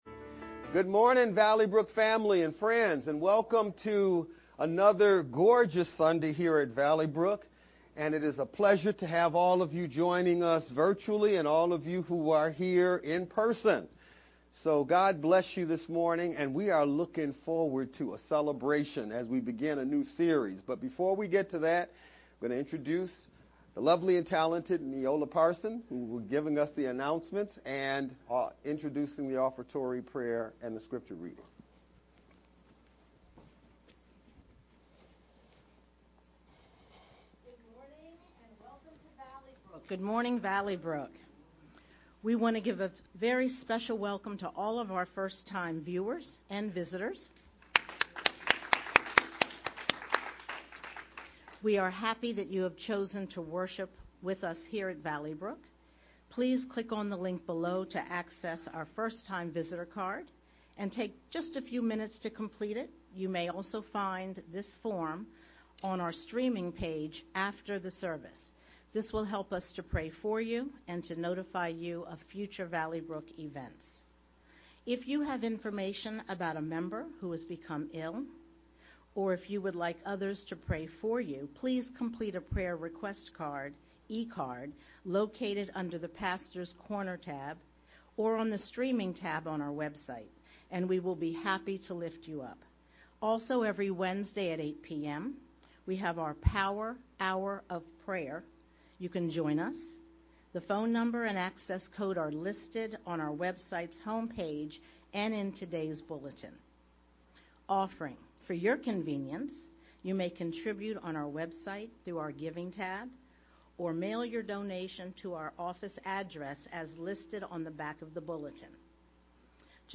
VBCC-Sermon-w-music-July-4-mp3-CD.mp3